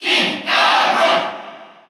Category: Crowd cheers (SSBU) You cannot overwrite this file.
King_K._Rool_Cheer_Russian_SSBU.ogg